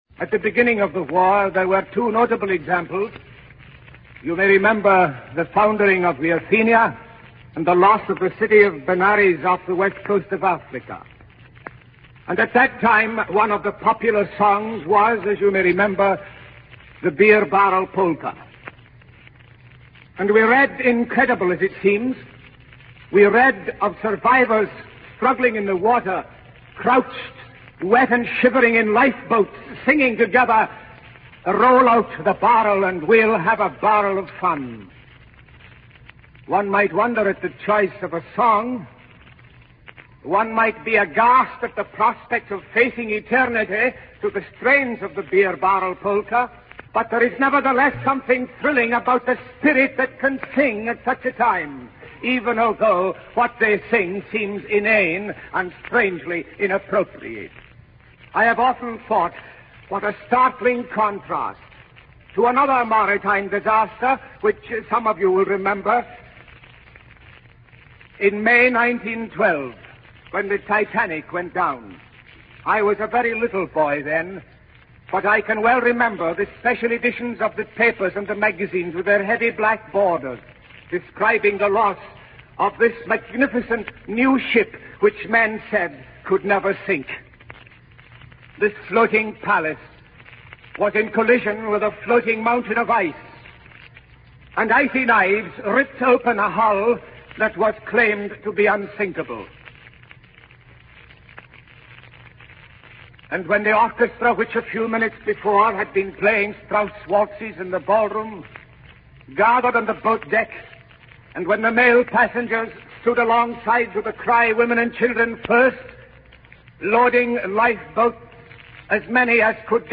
The sermon contrasts the faith of two maritime disasters, the Athenia and the Titanic, and encourages listeners to learn from the wisdom of the oyster, which turns trouble into beauty through patience and trust in the Spirit of God.